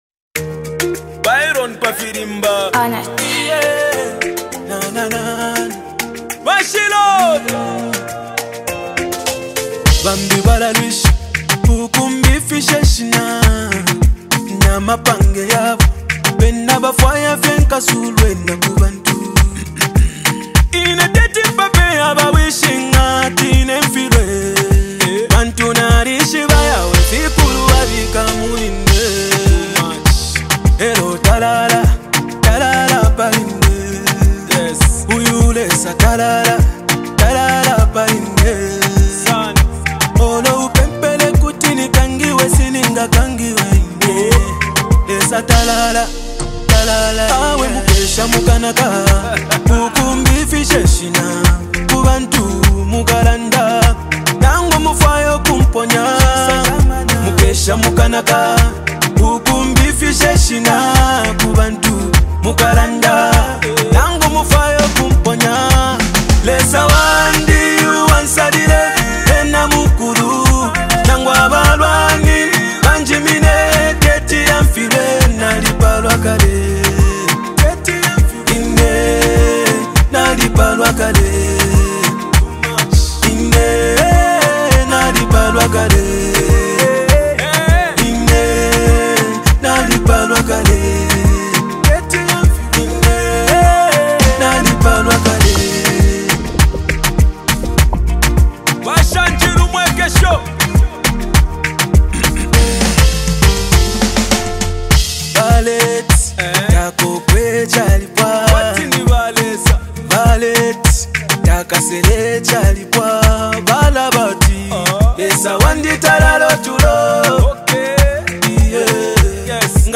single
In this heartfelt track